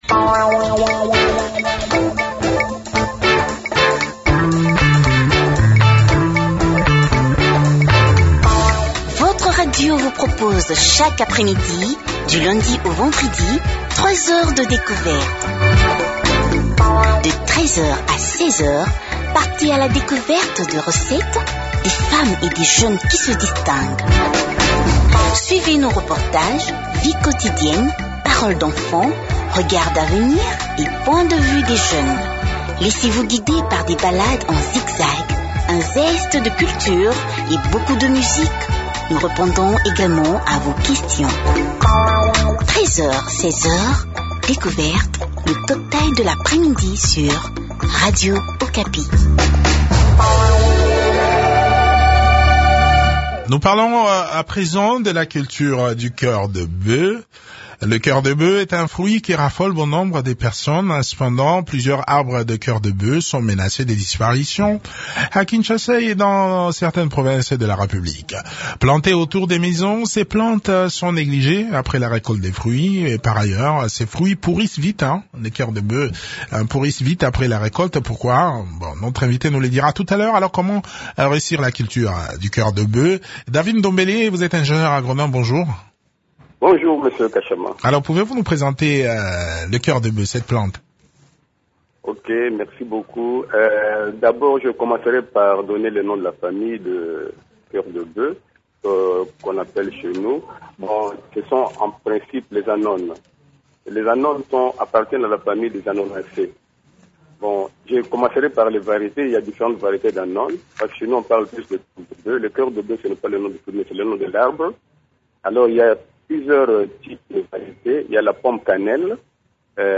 entretien
ingénieur agronome